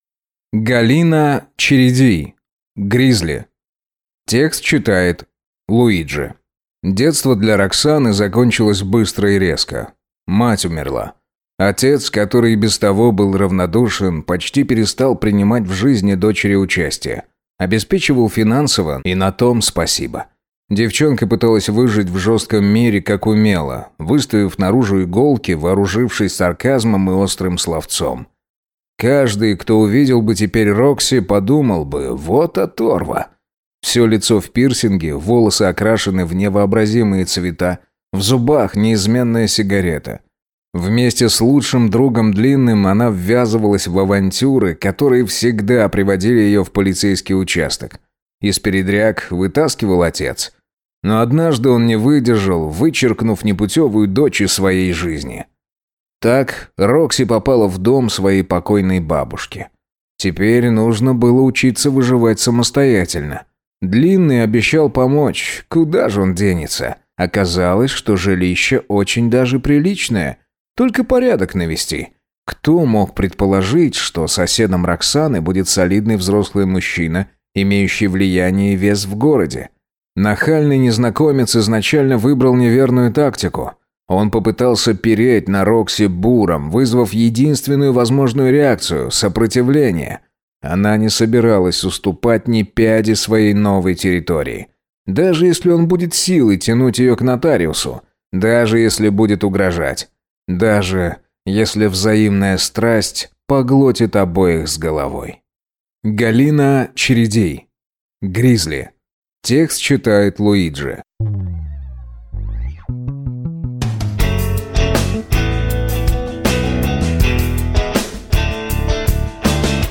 Аудиокнига Гризли | Библиотека аудиокниг